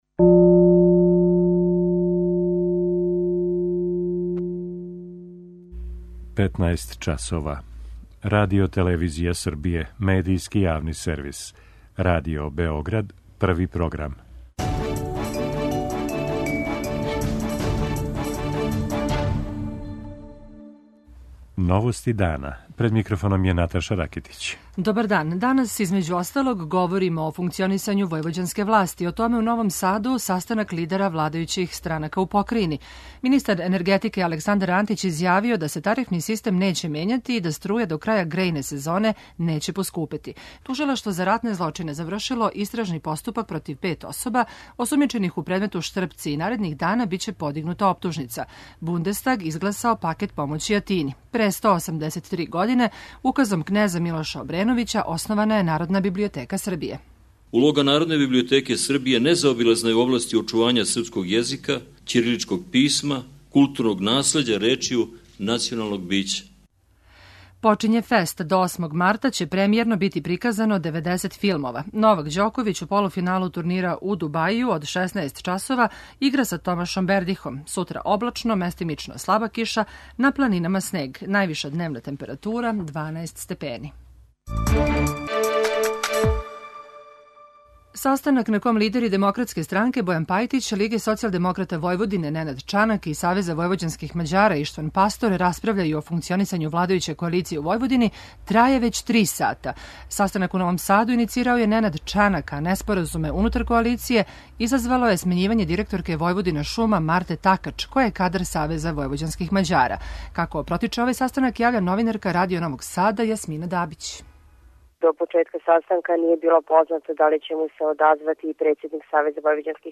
О проблемима у војвођанској владајућој коалицији, у Новом Саду разговарају лидер Лиге социјалдемократа Војводине Ненад Чанак, председник Демократске странке Бојан Пајтић и лидер Савеза војвођанских Мађара Иштван Пастор.